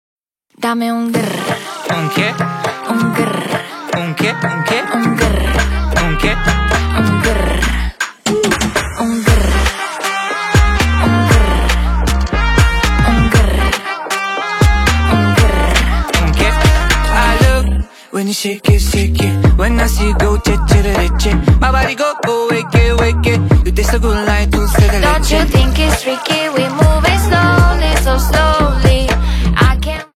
Get this energizing song
who smashed the banger anthem remarkably.